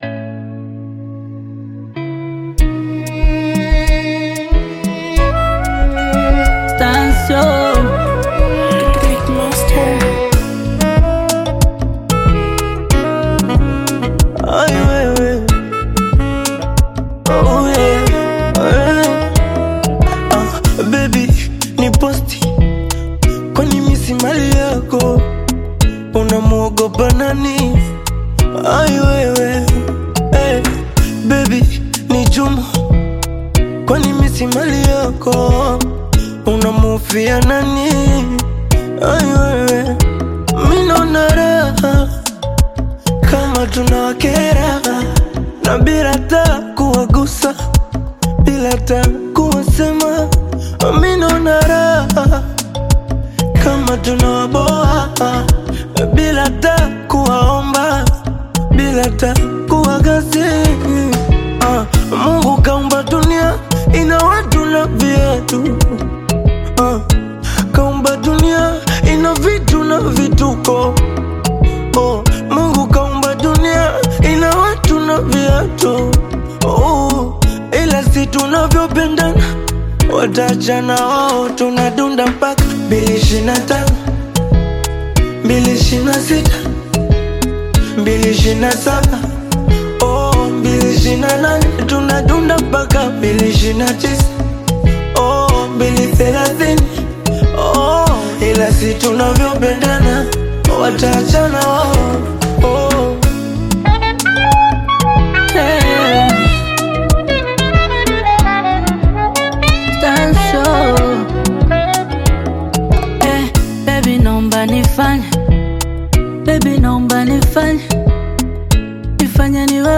Bongo Flava
Tanzanian Bongo Flava artist